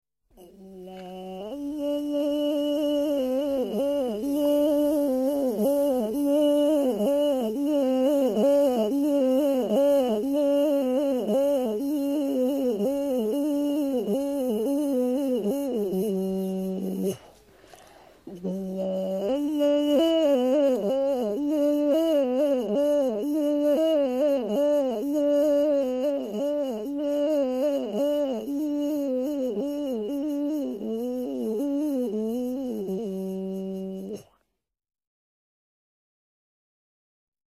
jeu_vocal.mp3